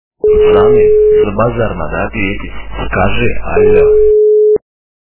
При прослушивании Крутой парень - Пацаны, за базар надо ответить. Скажи Альо! качество понижено и присутствуют гудки.